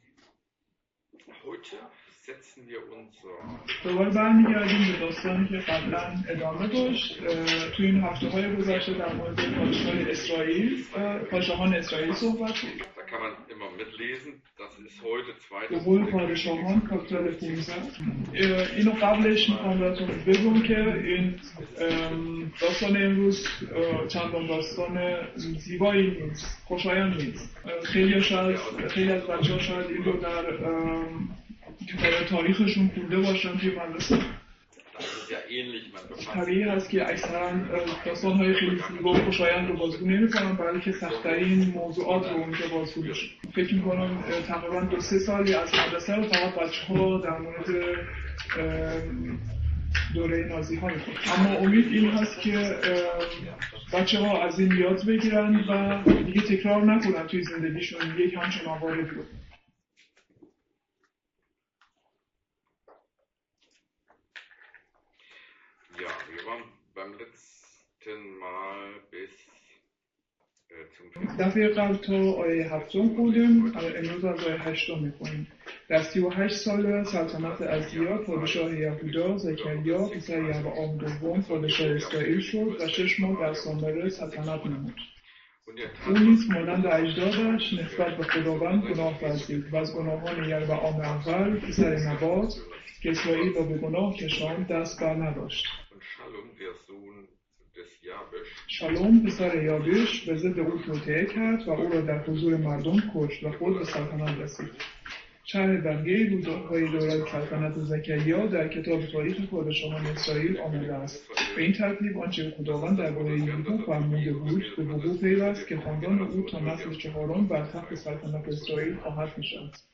Könige 15.8 -24 | Übersetzung in Farsi